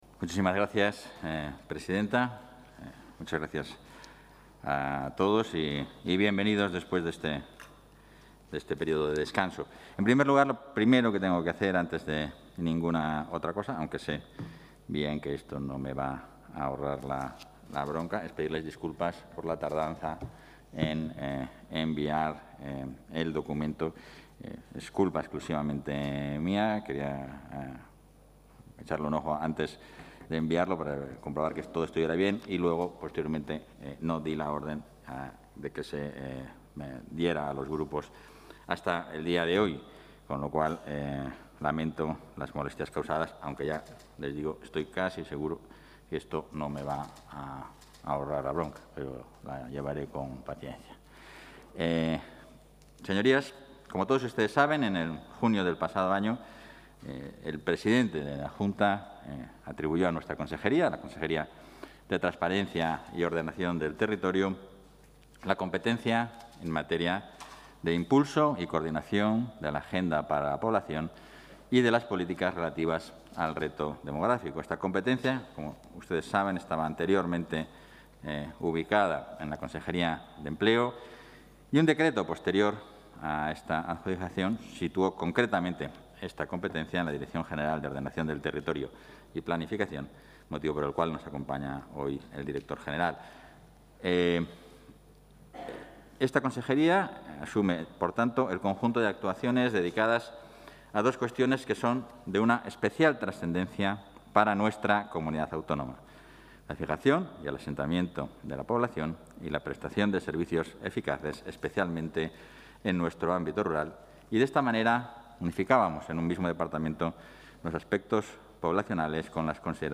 Comparecencia de Francisco Igea ante las Cortes para realizar la evaluación final de la Agenda de la Población 2010-2020
Intervención vicepresidente.
El vicepresidente, portavoz y consejero de Transparencia, Ordenación del Territorio y Acción Exterior, Francisco Igea, ha comparecido esta tarde ante las Cortes de Castilla y León dentro de la comisión de Transparencia y Ordenación del Territorio para exponer la evaluación final de la Agenda de la Población 2010-2020 así como las políticas relativas al reto demográfico, anteriormente coordinadas por la consejería de Empleo.